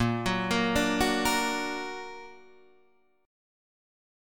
A# Augmented